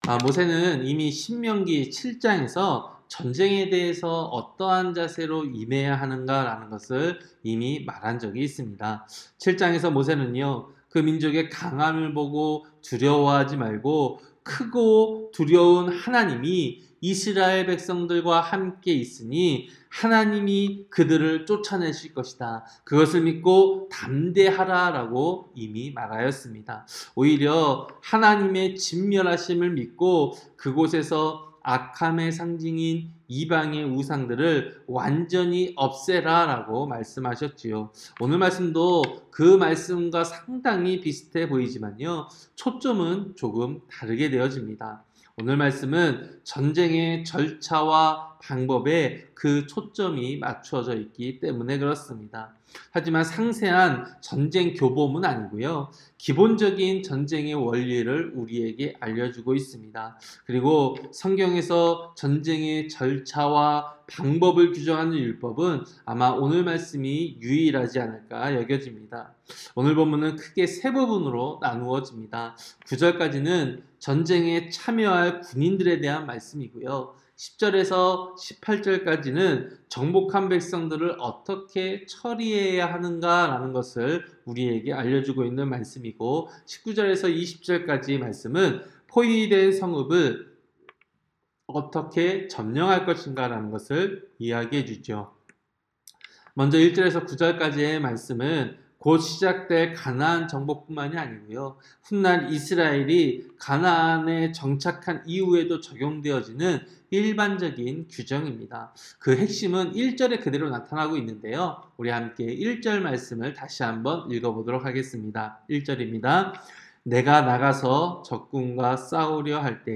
새벽설교-신명기 20장